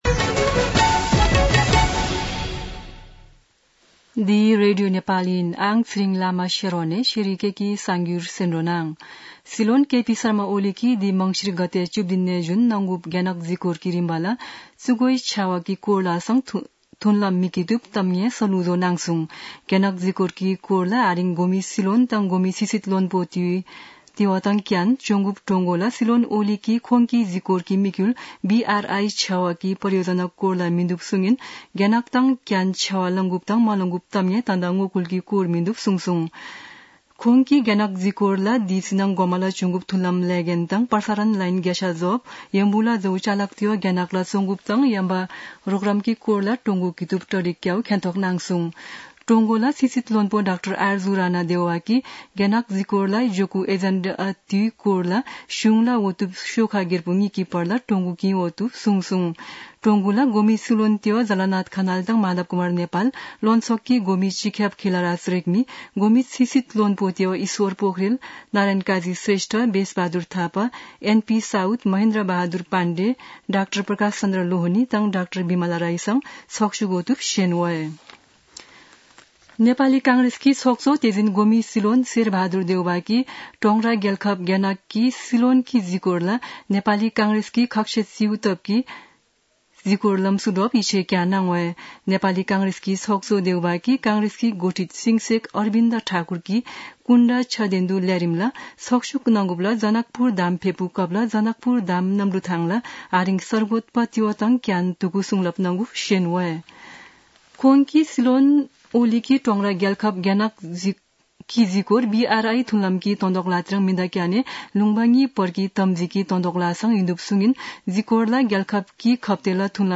शेर्पा भाषाको समाचार : ११ मंसिर , २०८१
4-pm-Sherpa-news-1-3.mp3